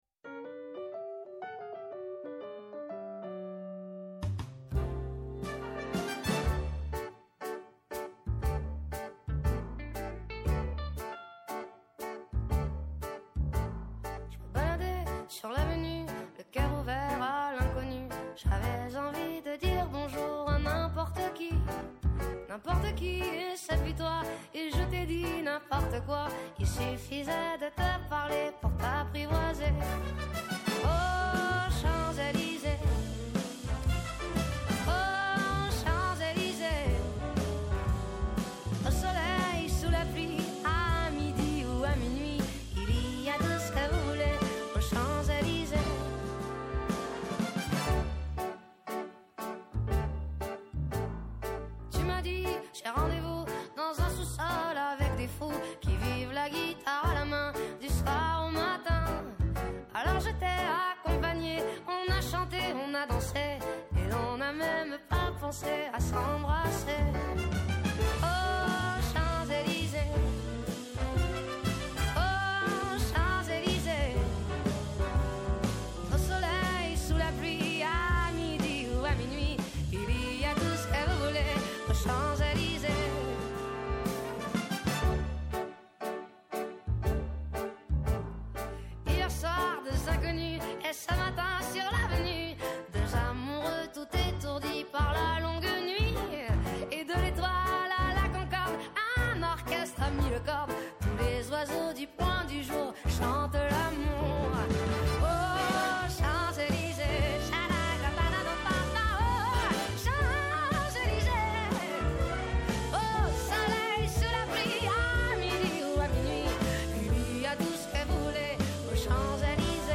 Και Ναι μεν η ματιά μας στρέφεται στην εγχώρια επικαιρότητα, Αλλά επειδή ο κόσμος “ο μικρός ο μέγας” -όπως το διατύπωσε ο ποιητής- είναι συχνά ο περίγυρός μας, θέλουμε να μαθαίνουμε και να εντρυφούμε στα νέα του παγκόσμιου χωριού. Έγκριτοι επιστήμονες, καθηγητές και αναλυτές μοιράζονται μαζί μας τις αναλύσεις τους και τις γνώσεις τους.